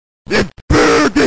fatal1gen-reppuken.mp3